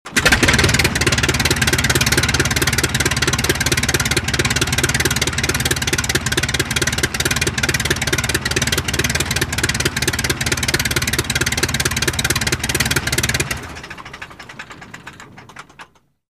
Звук биплана работа двигателя
Шум биплана мотор звук
Биплан звук мотора работа